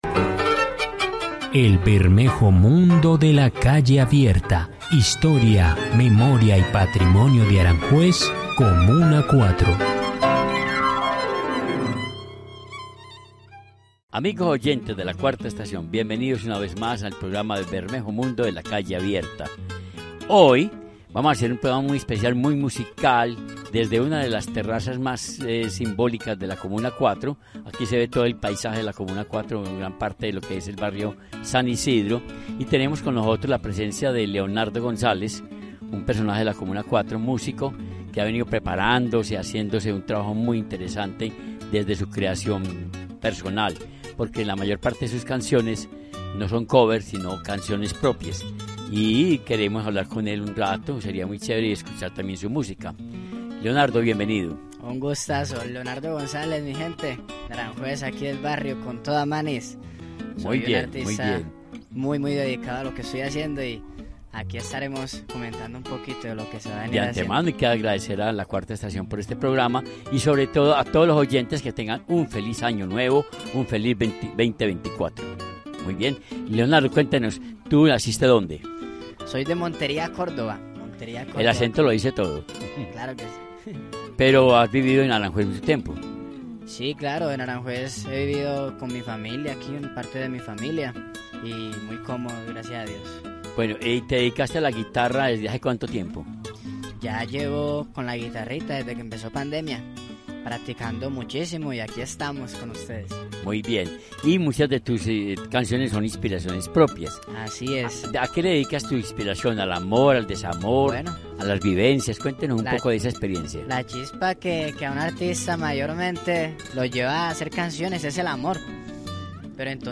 Disfruta y conoce algunas de sus composiciones en este espacio